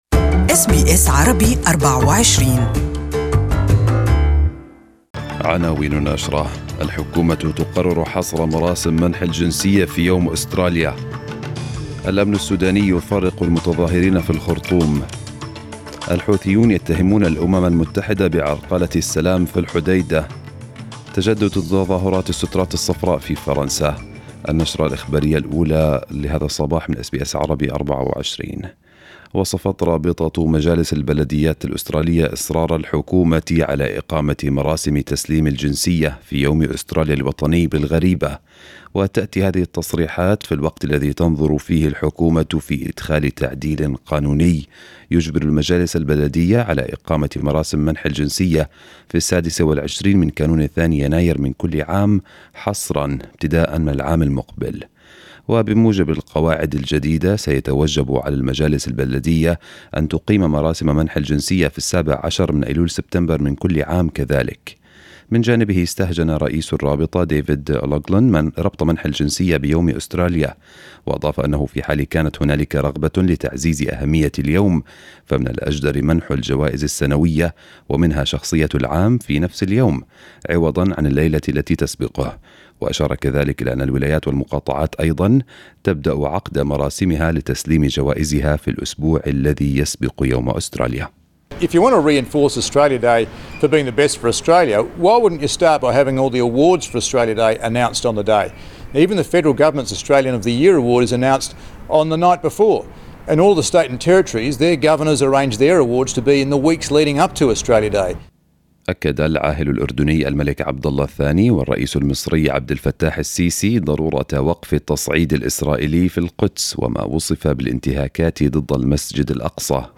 نشرة الأخبار المفصلة لهذا الصباح